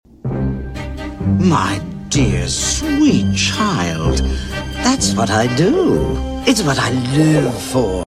Classic 2 Hair Clipper IHC 33 Sharp sound effects free download